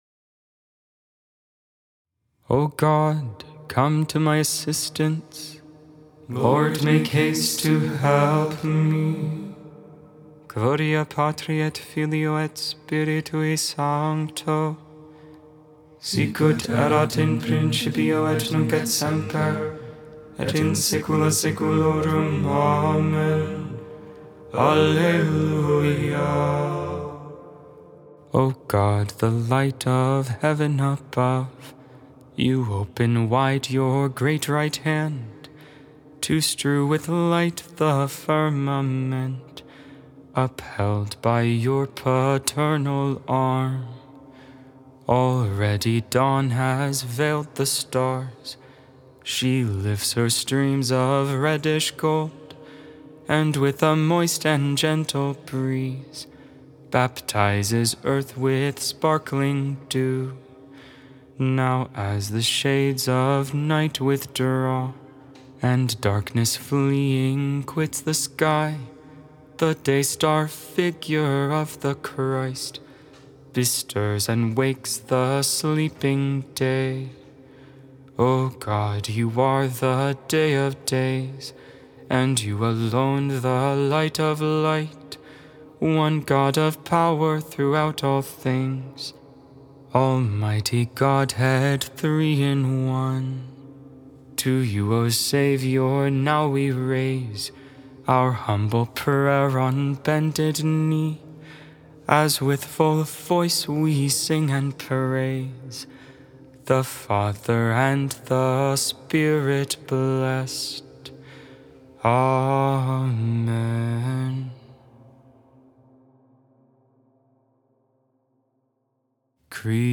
Ambrosian hymn ca. 5th century.